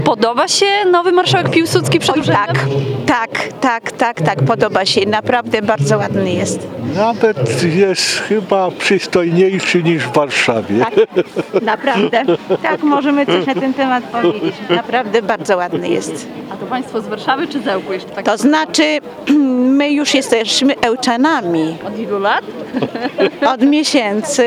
– Marszałek Piłsudski prezentuje się znacznie lepiej, niż ten w stolicy- to ocena małżeństwa, które pół roku temu przeprowadziło się do Ełku z Warszawy.